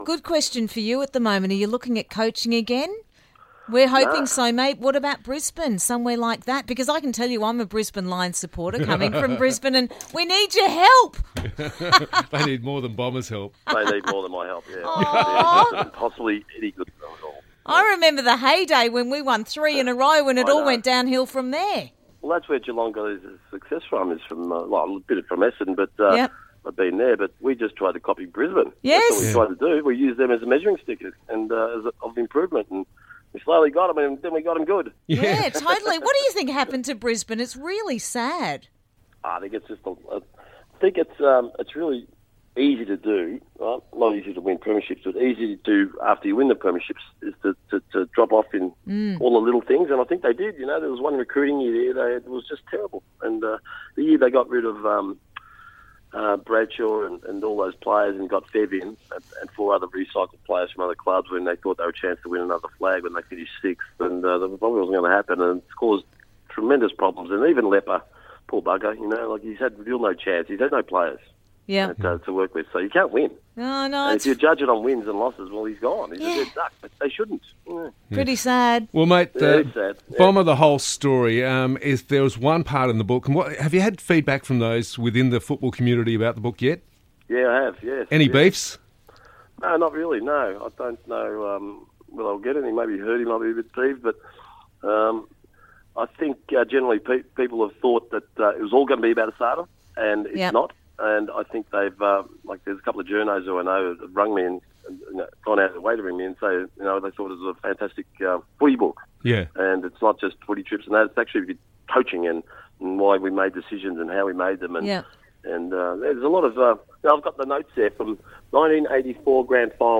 Interview - Mark Bomber Thompson Part 2